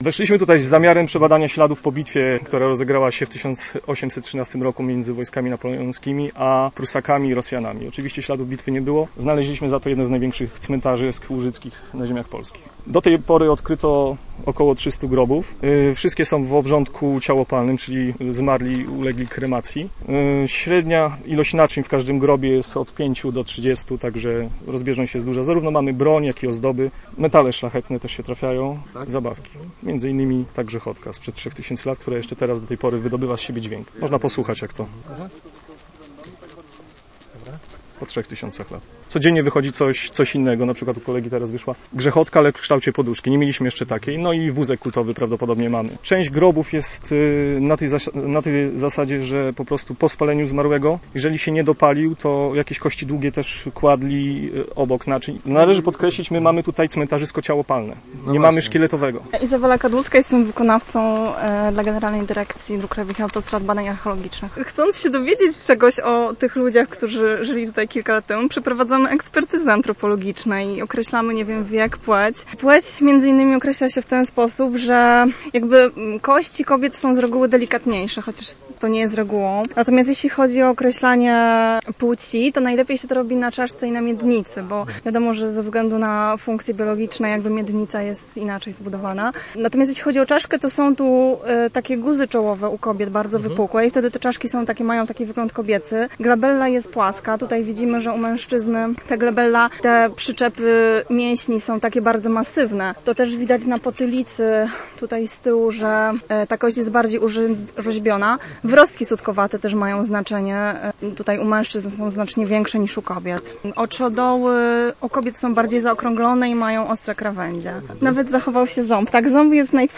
Odgłos takiej grzechotki sprzed 4 tysięcy lat - doznanie bezcenne.
Posłuchaj grzechotki sprzed 4 tysięcy lat: